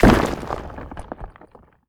rock_smashable_hit_impact_01.wav